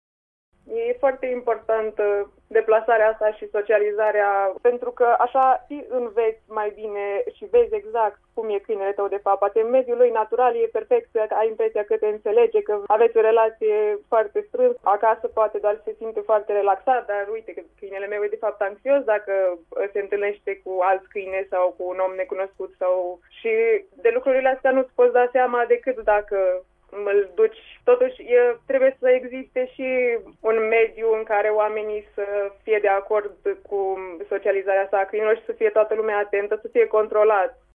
Consultant în comportamentul animalelor